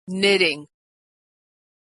Voiceless /t/
When /t/ is stressed, it has a puff of air.
When /t/ is not stressed, it does not have a puff of air.